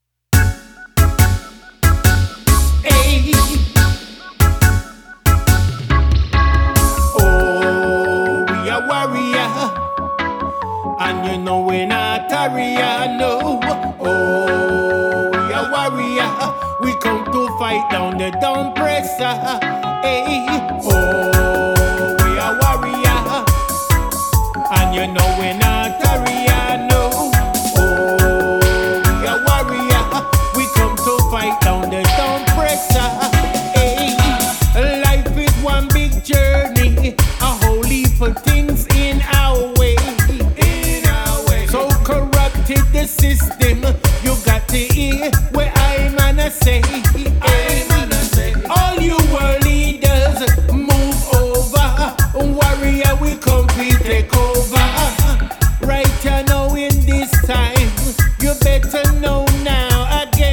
on vocal
killer steppers relick